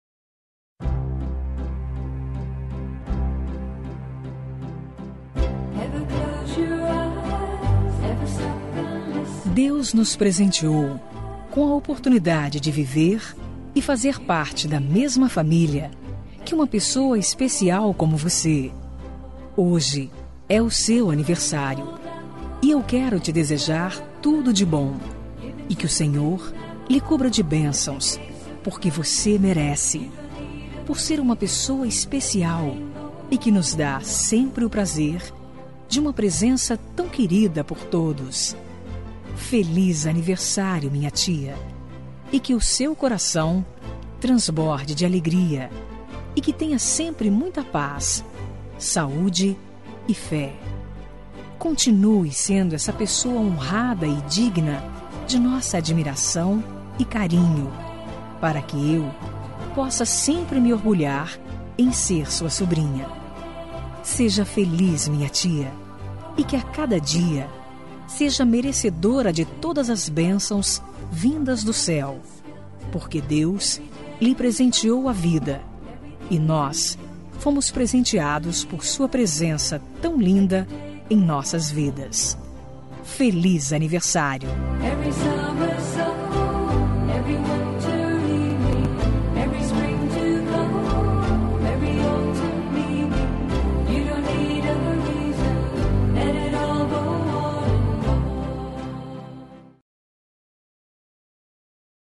Aniversário de Tia – Voz Feminina – Cód: 9590